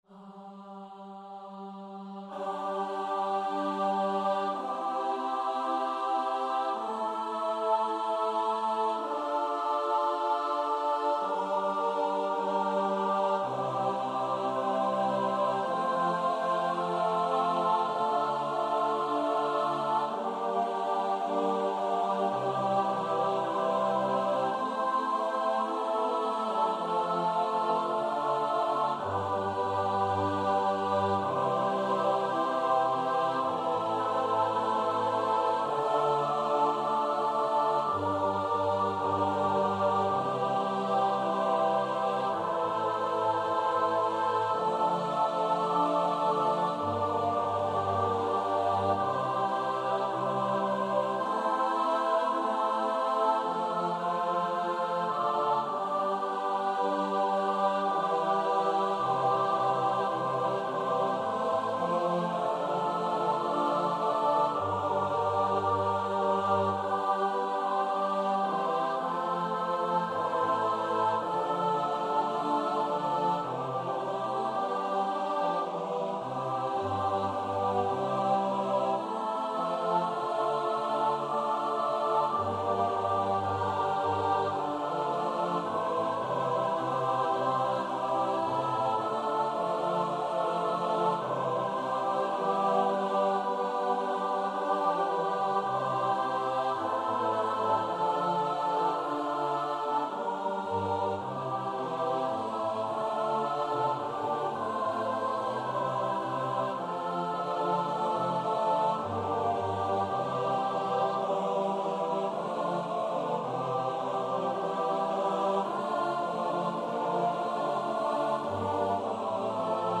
Number of voices: 7vv Voicing: SSAATTB Genre: Sacred, Motet
Language: Latin Instruments: A cappella